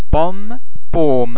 The French [ o ] sound can also be almost as open as the vowel sound in English words like otter, lot.
o_pomme.mp3